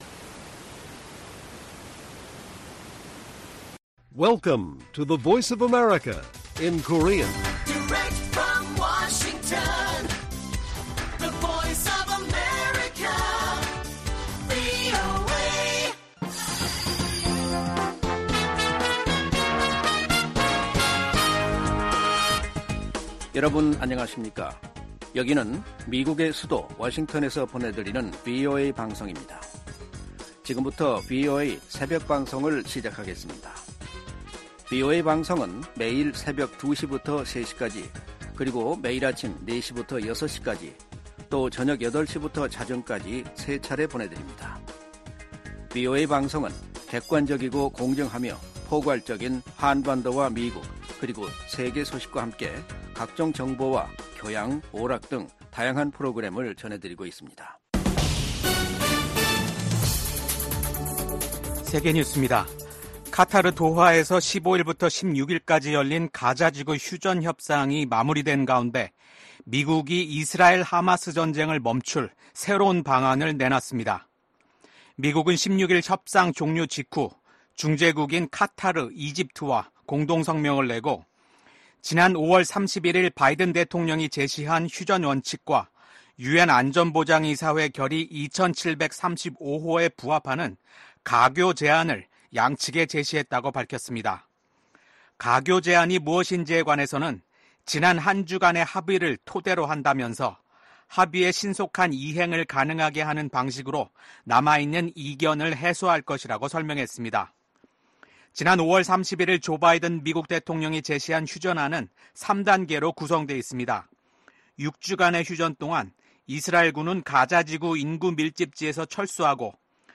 VOA 한국어 '출발 뉴스 쇼', 2024년 8월 17일 방송입니다. 미국 정부가 일본 고위 당국자들의 야스쿠니 신사 참배를 “과거 지향적”이라고 평가했습니다. 백악관 당국자가 연내 미한일 3국 정상회담 개최 가능성을 거론했습니다.